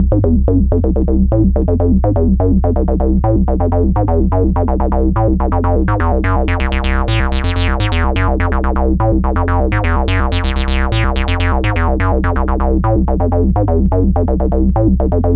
cch_acid_oldskool_125.wav